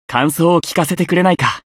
觉醒语音 感想を聞かせてくれないか 媒体文件:missionchara_voice_260.mp3